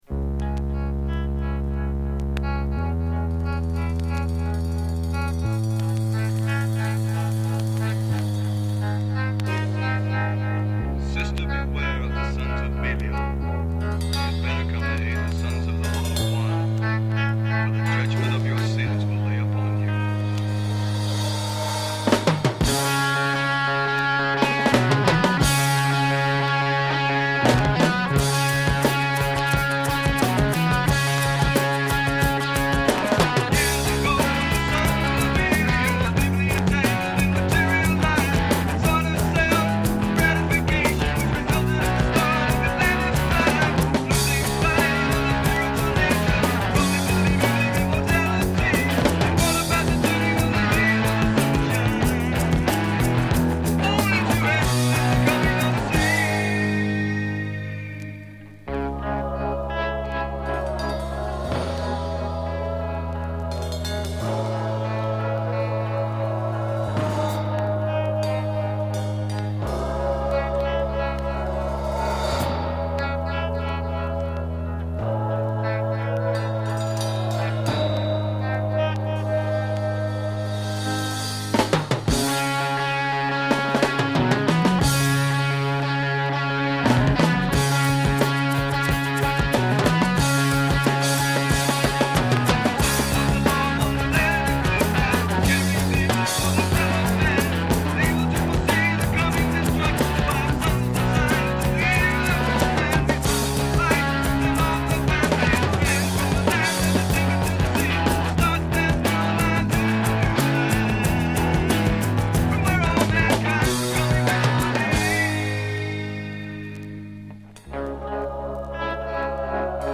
Just great guitar throughout and snappy drums too.